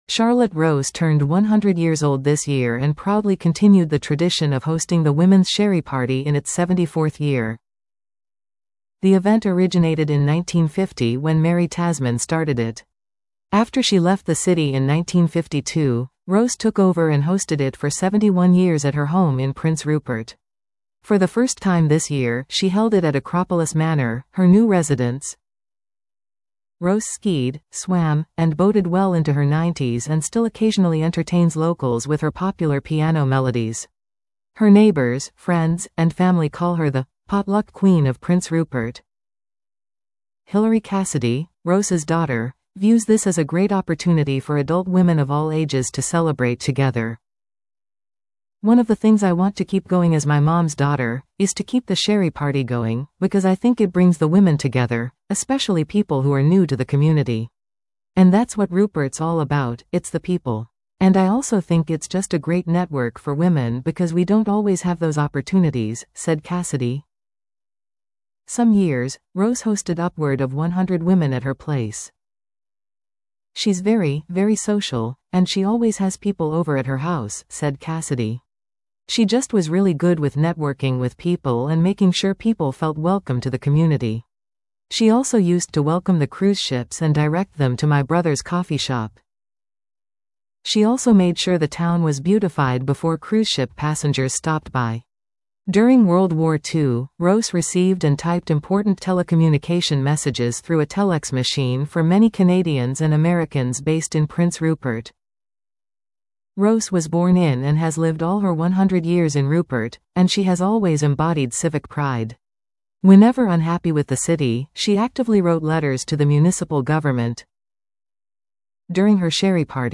Christmas jingles
while the crowd sang along
The evening was a joyful mix of piano melodies, delicious treats, carolling, and heartfelt conversations.
During her sherry party, she joyfully played the piano on popular tunes such as “Jingle Bells” and “Deck the Halls” as the attendees sang along.